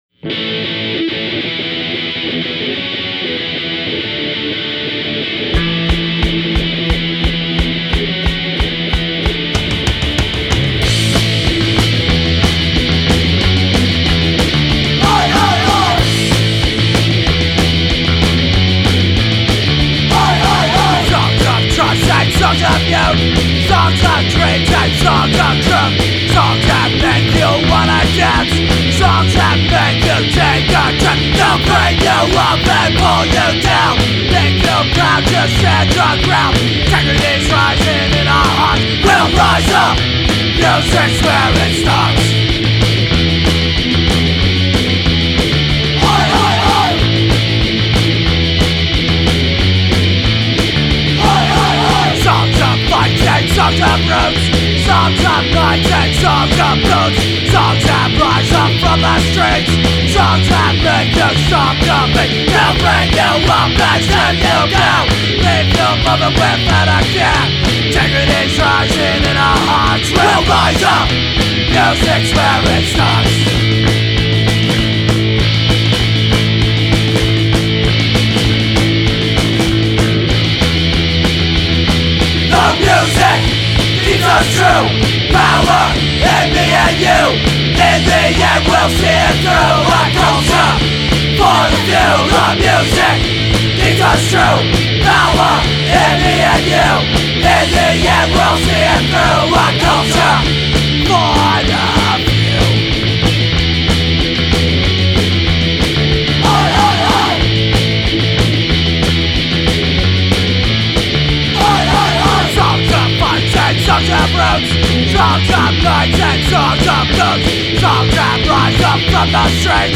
punk rock See all items with this value